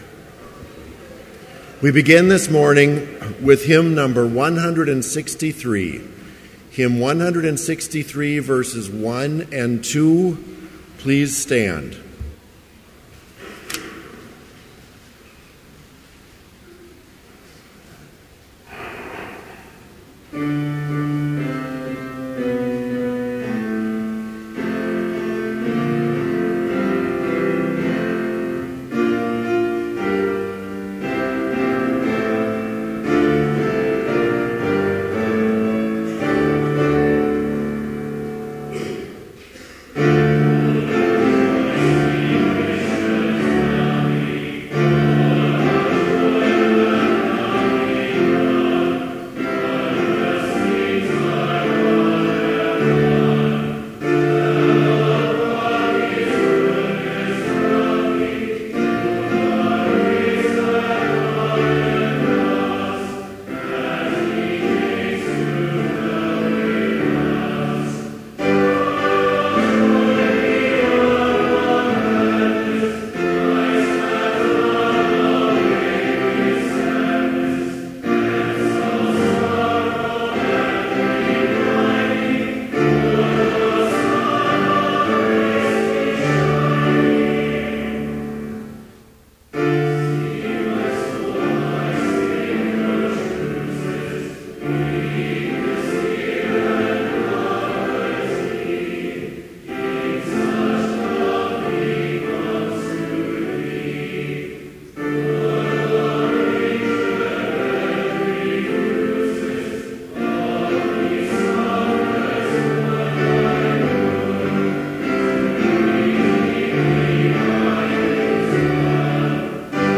Complete service audio for Chapel - March 25, 2014